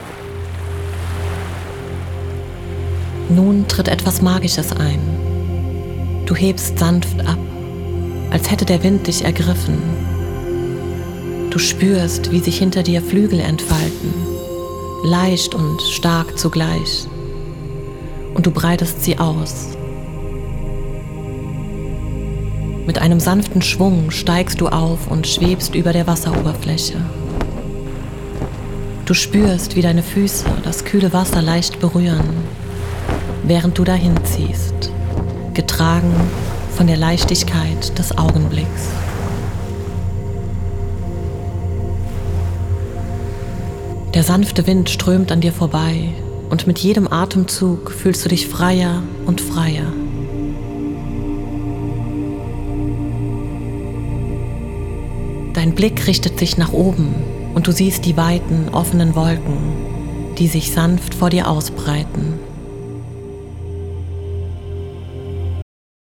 Frequenz: 528 Hz – Fördert inneren Frieden und Selbstbewusstsein.
8D-Musik: Lässt die Meeresklänge um dich tanzen und verstärkt das Gefühl von Ruhe.